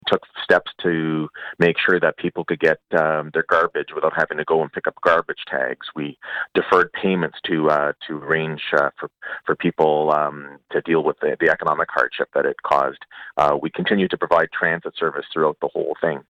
Mayor Mitch Panciuk tells Quinte News the city was able to give some additional assistance to the residents.